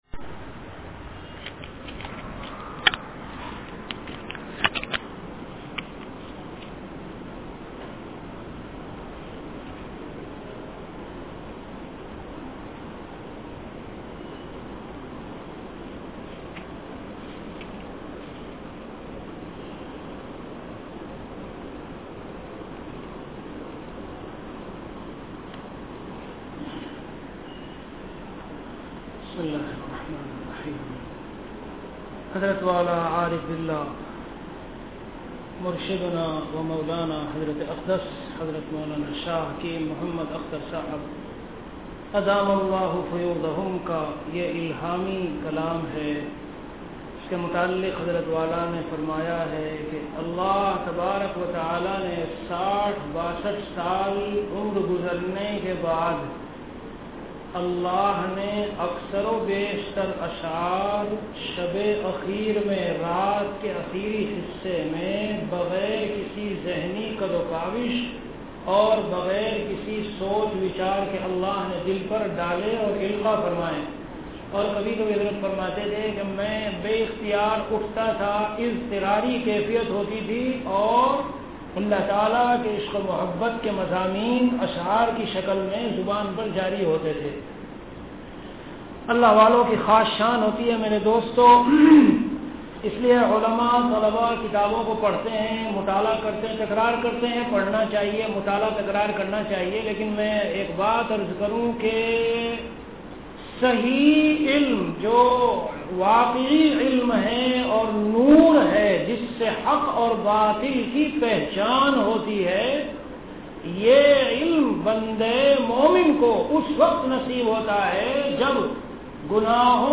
Delivered at Khanqah Imdadia Ashrafia.